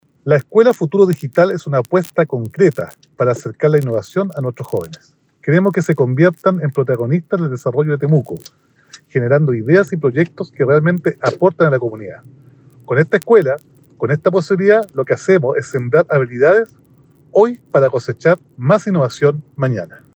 Roberto-Neira-alcalde-de-Temuco-24.mp3